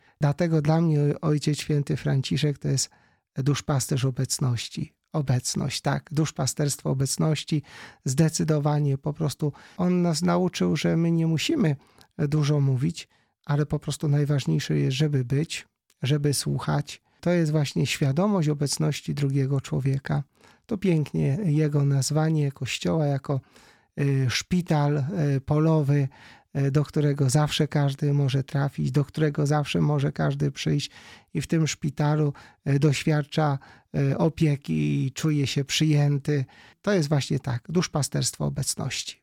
W rozmowie z Radiem Rodzina opowiedział nie tylko o wspomnieniach jakie pozostaną w nim po papieżu, ale też o przesłaniu jego pontyfikatu.